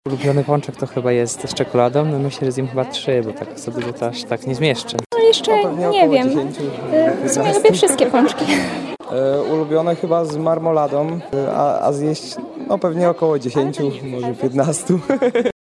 Zapytaliśmy mieszkańców, jakie są ich ulubione pączki.
sonda_ulubiony_paczek.mp3